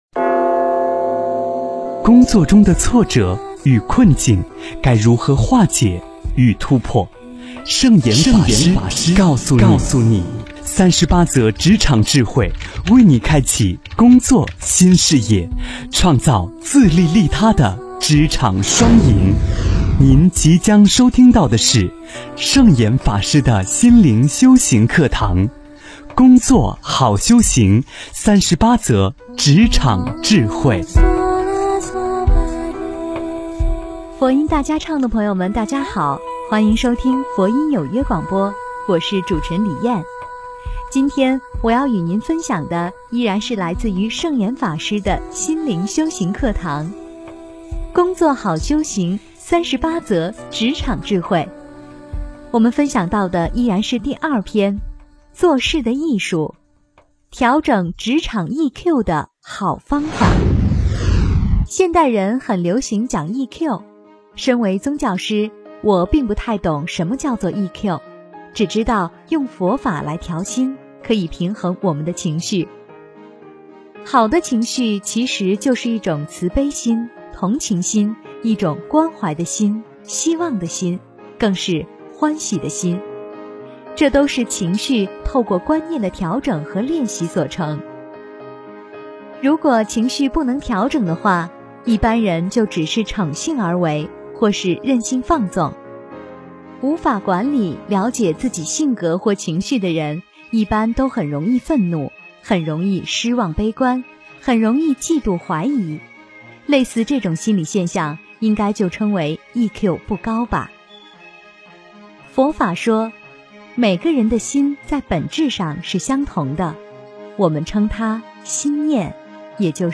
职场20调整职场EQ的好方法--佛音大家唱 真言 职场20调整职场EQ的好方法--佛音大家唱 点我： 标签: 佛音 真言 佛教音乐 返回列表 上一篇： 职场16争取时间活在当下--佛音大家唱 下一篇： 职场21真正的悠闲--佛音大家唱 相关文章 寒山上的石观音--孟庭苇 寒山上的石观音--孟庭苇...